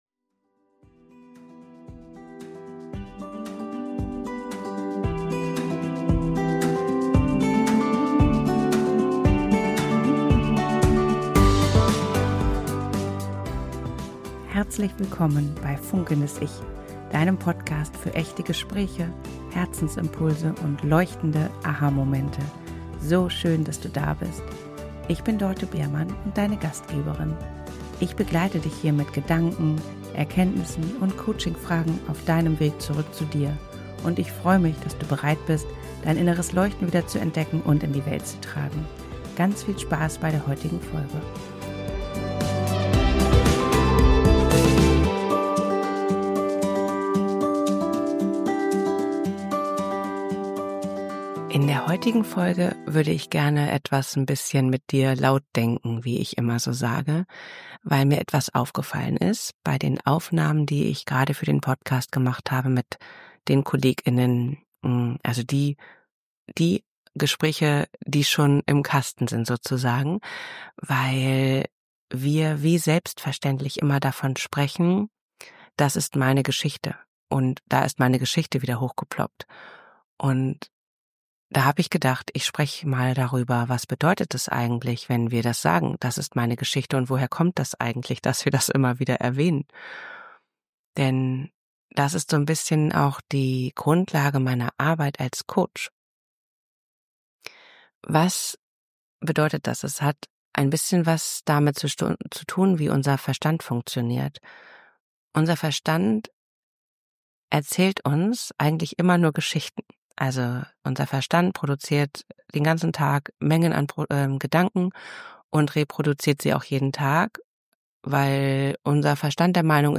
In dieser Solofolge denke ich laut mit dir über genau das nach. Ich nehme dich mit in meine eigene Geschichte – und zeige dir, wie wir beginnen können, sie neu zu schreiben.